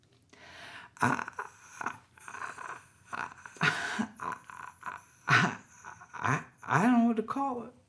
Emotion, Emission, Expressive Tint